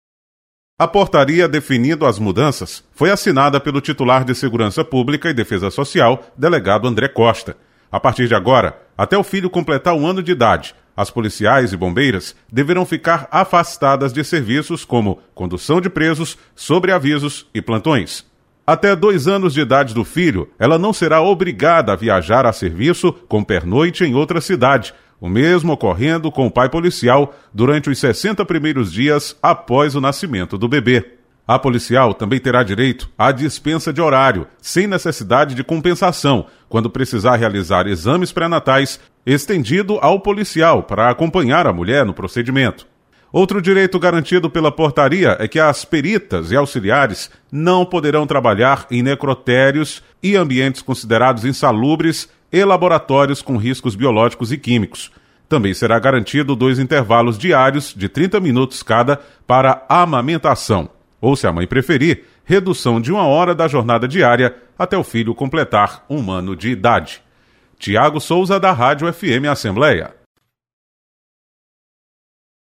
As policiais civis e militares, bombeiras militares e peritas criminais passam a ter mais direitos quando em fase de gestação. Repórter